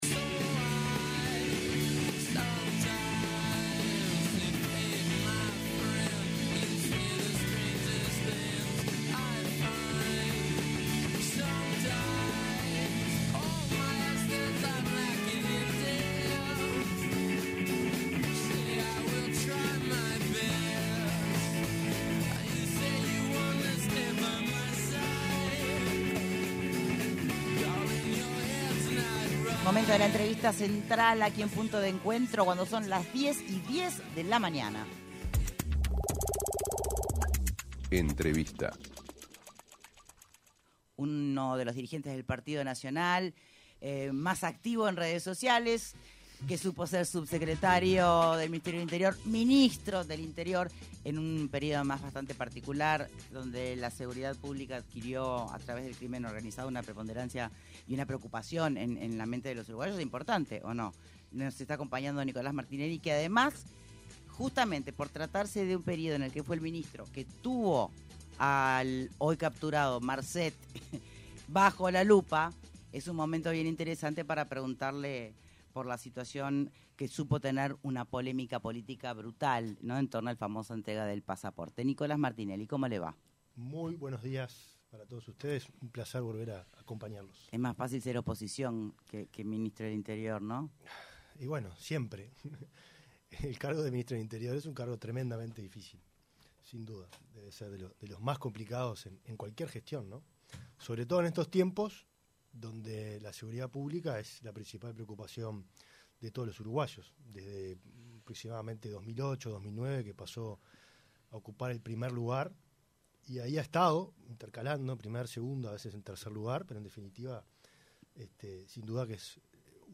ENTREVISTA: NICOLÁS MARTINELLI